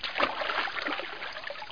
00217_Sound_paddle.mp3